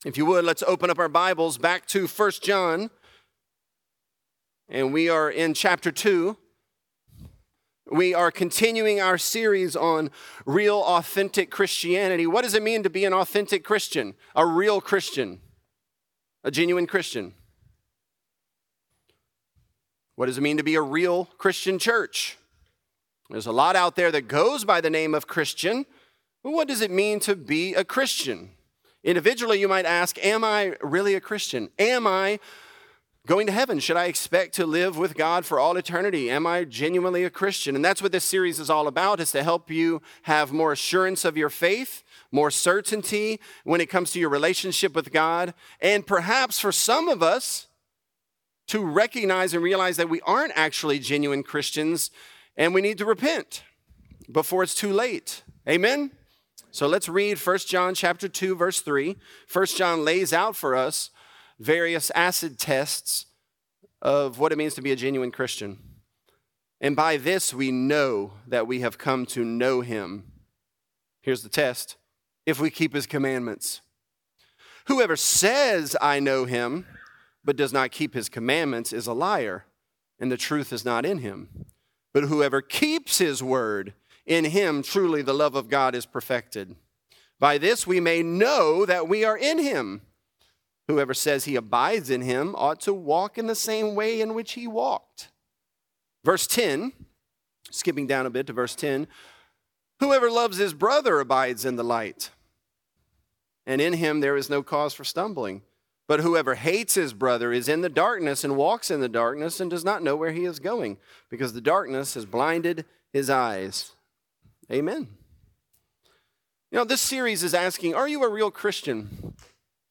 Authentic: Objective Christianity | Lafayette - Sermon (1 John 2)